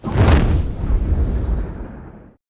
fire3.wav